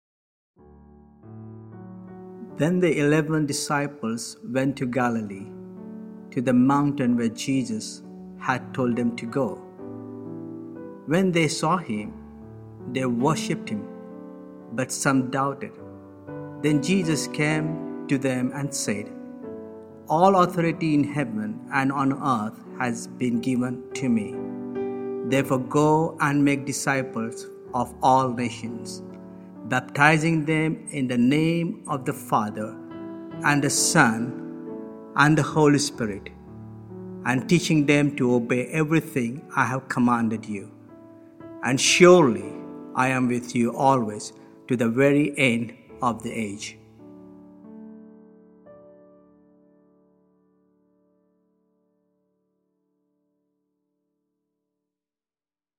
The reading uses the NIV bible.